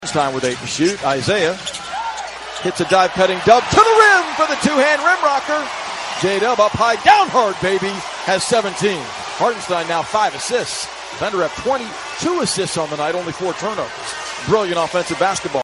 Thunder PBP Dub DUnk 5-8.mp3